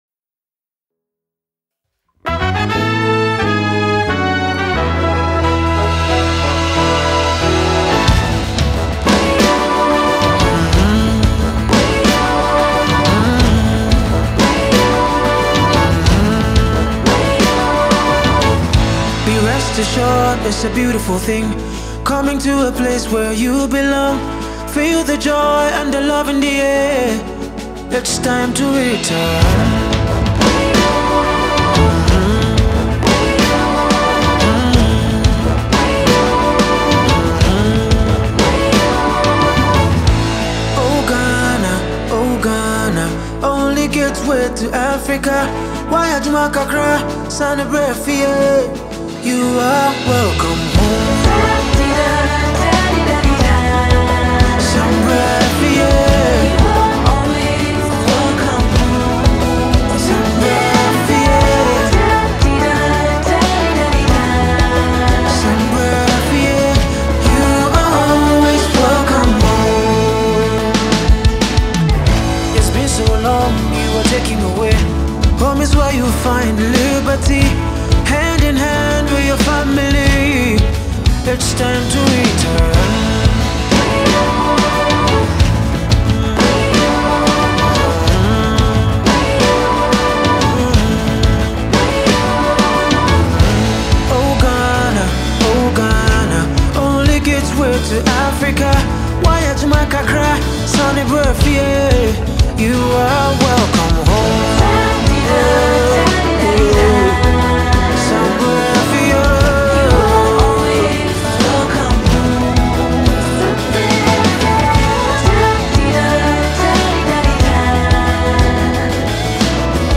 Silky voice Singer